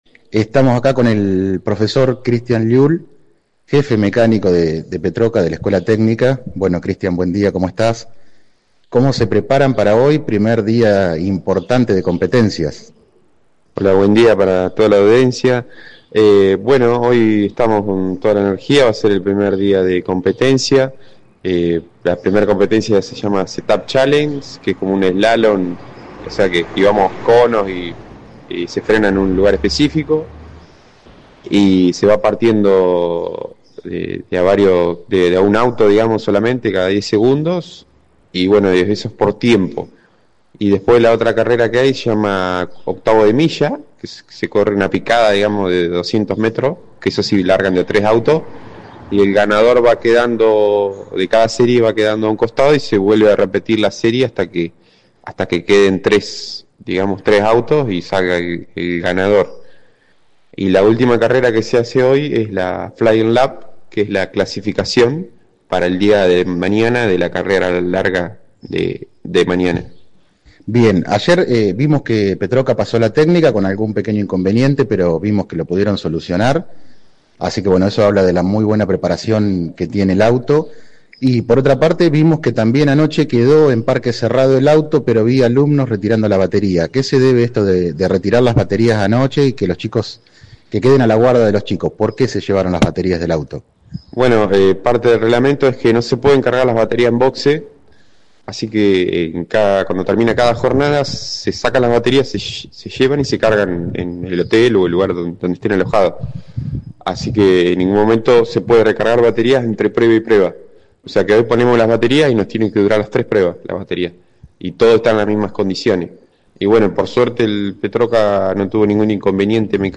AUDIO COMPLETO DE LA ENTREVISTA